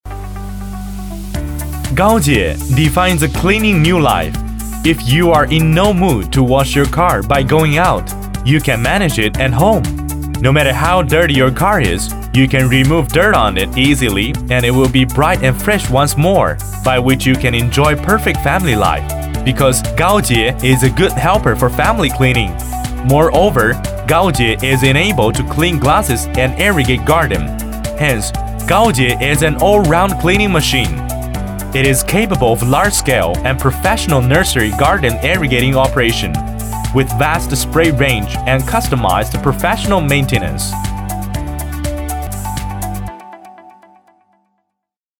男40号配音师
十余年从业经验，精通中文，日文，英文，声音浑厚，庄重，大气。
英文-男40-激情活力 产品介绍.mp3